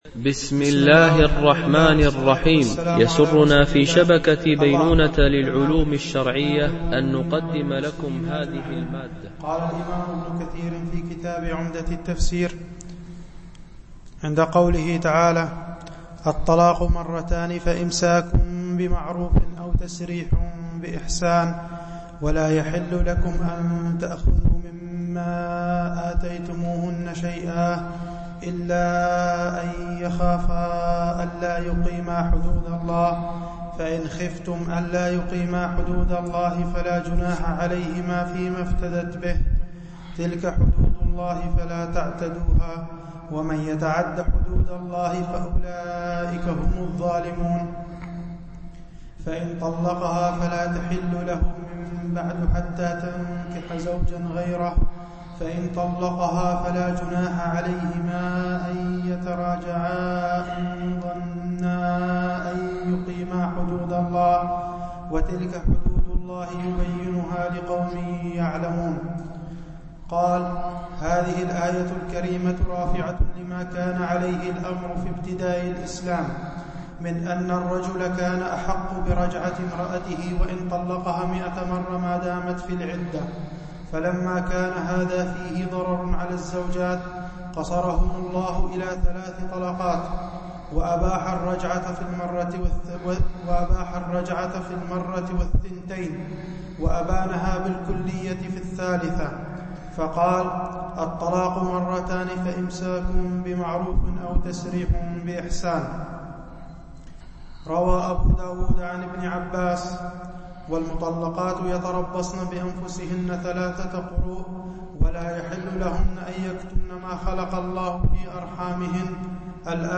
شرح مختصر تفسير ابن كثير(عمدة التفسير) الدرس 37 (سورة البقرة الآية 229-232)
MP3 Mono 22kHz 32Kbps (CBR)